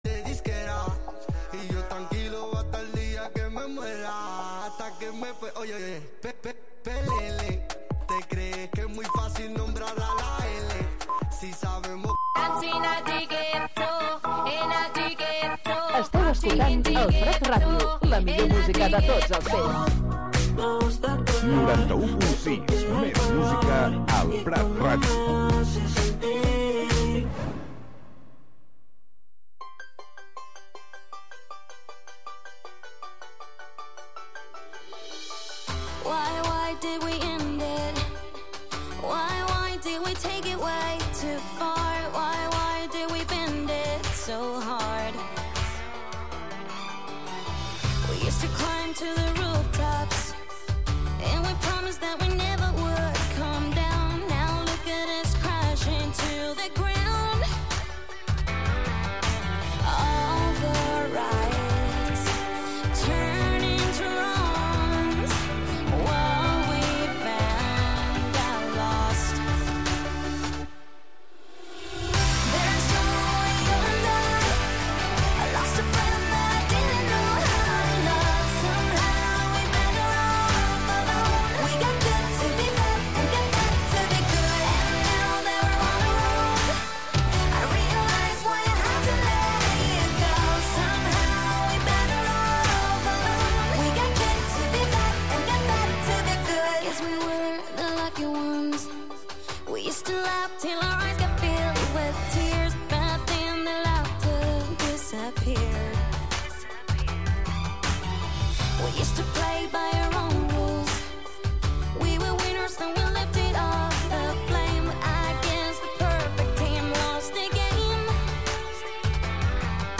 Recorreguts musicals pels racons del planeta, música amb arrels i de fusió. Sense prejudicis i amb eclecticisme.